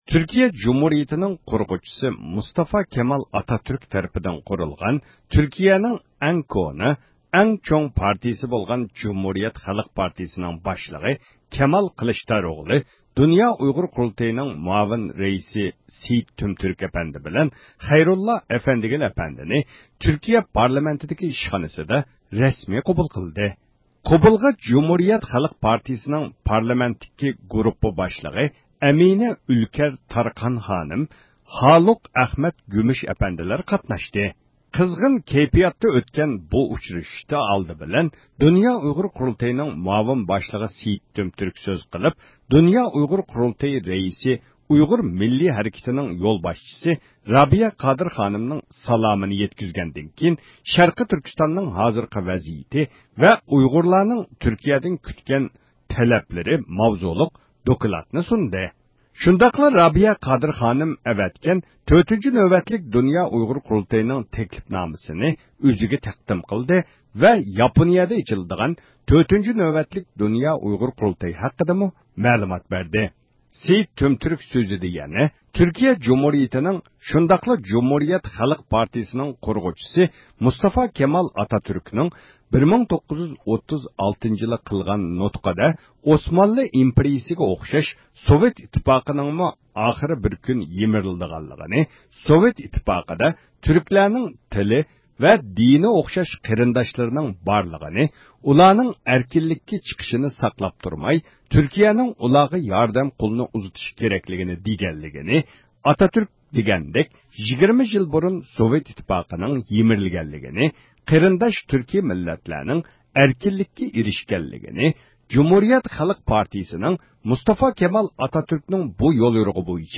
قىزغىن كەيپىيات ئىچىدە ئۆتكەن بۇ ئۇچرىشىش ئاخىرىدا مىكروفونىمىزنى پارتىيە باشلىقى كەمال قىلىچتاروغلۇغا ئۇزاتتۇق.
تۈركىيە جۇمھۇرىيەت خەلق پارتىيىسى باشلىقى كەمال قىلىچتاروغلۇ ئىختىيارىي مۇخبىرىمىزنىڭ سوئاللىرىغا جاۋاب بەردى. 2012-يىلى ئاپرېل، تۈركىيە.